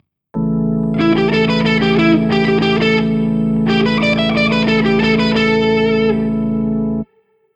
JASON BECKER ARPEGGI IN TAPPING